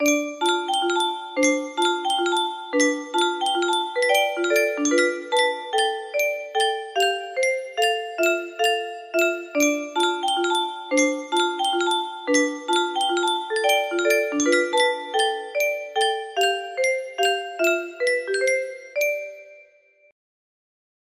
Levins Music Box music box melody